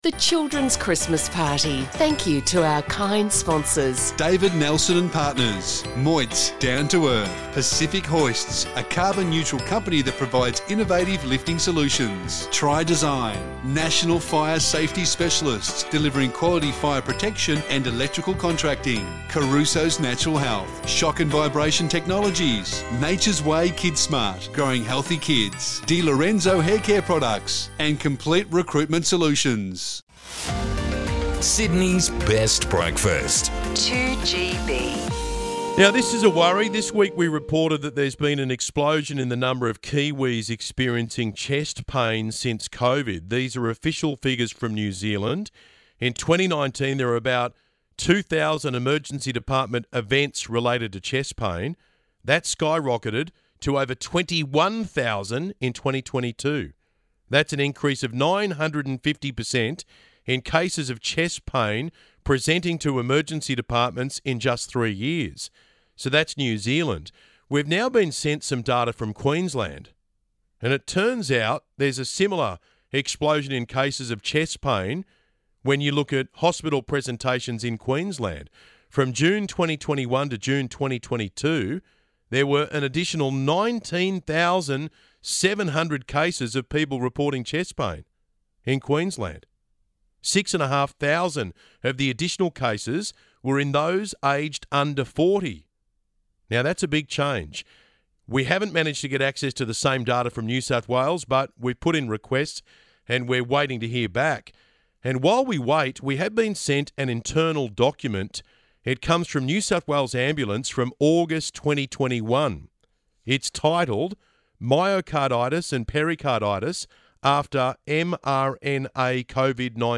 The following is the Ben Fordham show on 2GB.